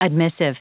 Prononciation du mot admissive en anglais (fichier audio)
Prononciation du mot : admissive